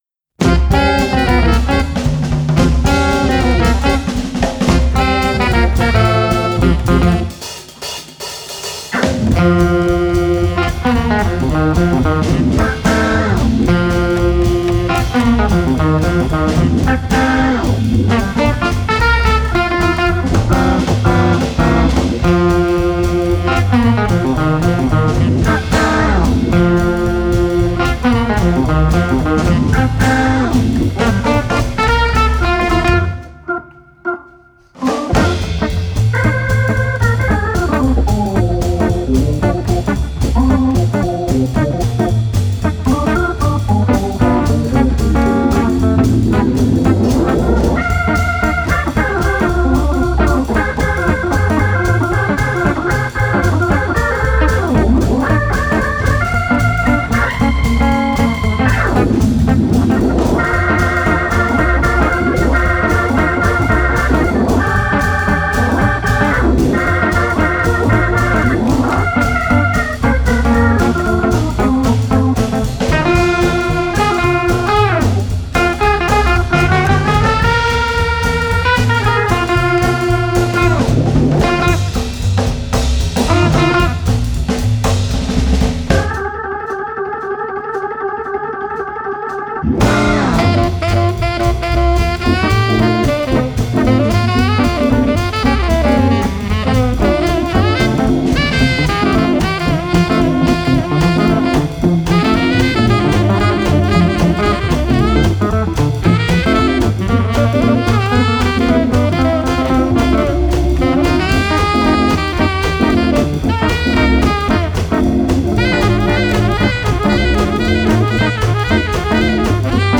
poursuit son voyage instrumental